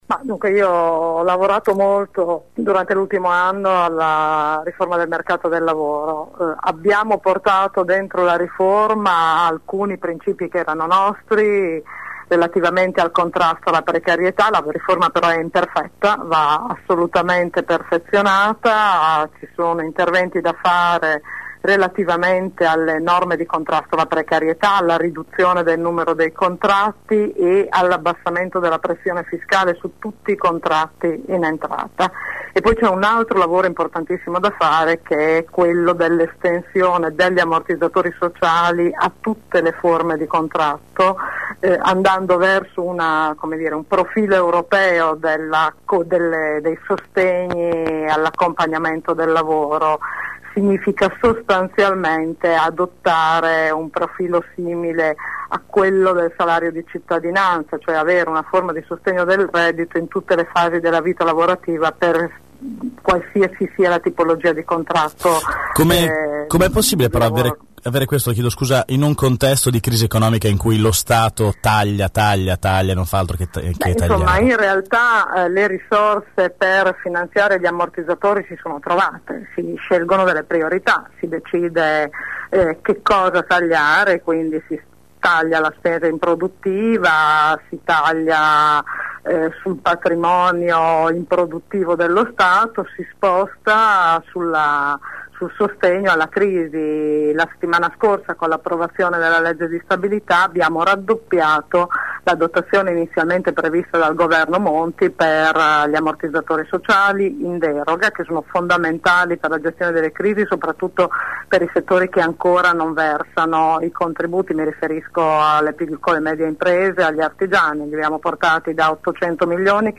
Venerdì mattina durante AngoloB abbiamo organizzato una tavola rotonda con alcuni dei candidati.
Per il Pd abbiamo intervistato:
Rita Ghedini, senatrice uscente